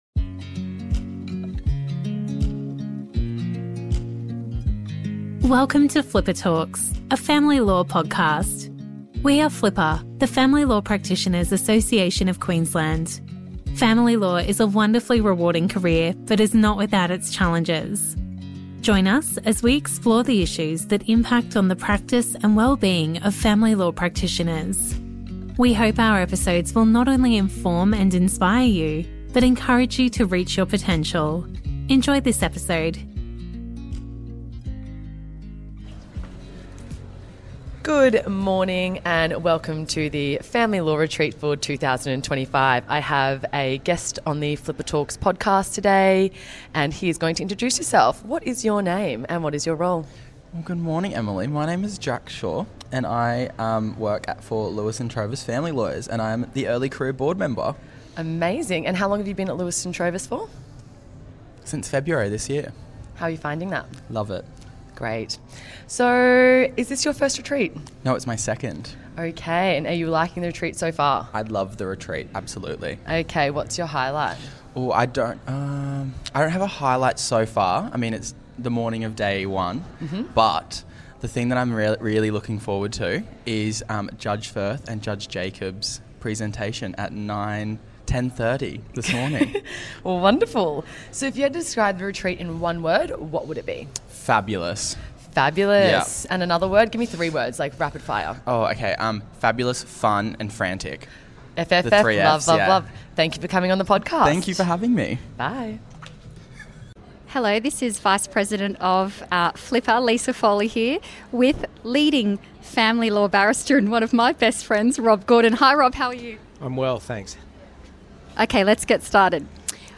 In this special episode of FLPA Talks: A Family Law Podcast, we hit the ground at the FLPA Retreat 2025 to bring you a lively vox pop straight from the action.